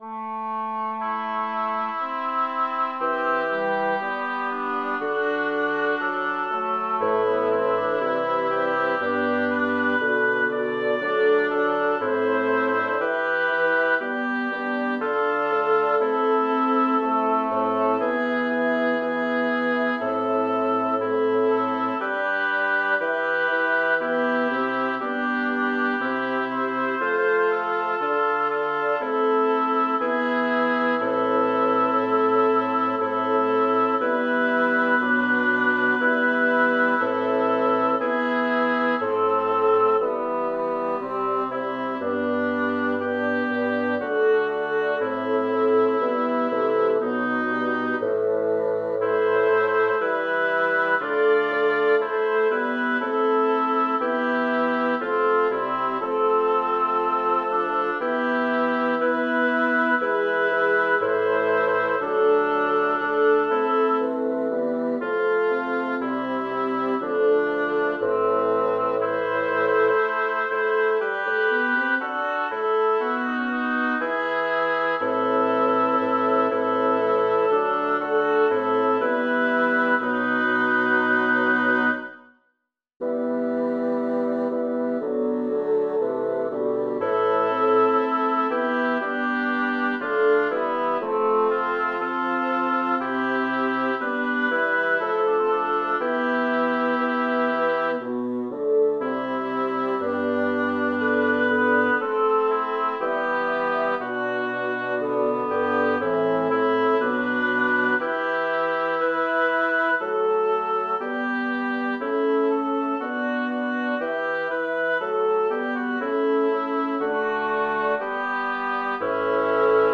Title: Tra bei rubini e perle Composer: Vincenzo Ruffo Lyricist: Giovanni Battista Amalteo Number of voices: 5vv Voicing: SSTTT Genre: Secular, Madrigal
Language: Italian Instruments: A cappella